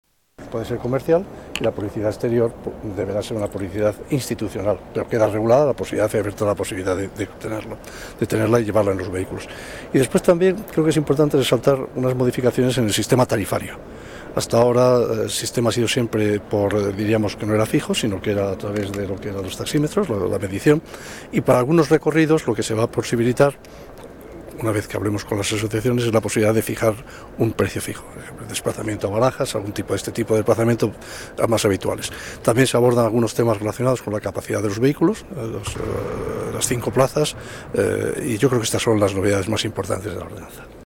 Nueva ventana:Declaraciones de Antonio de Guindos, delegado de Medio Ambiente